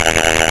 RbtStingeBuzz.wav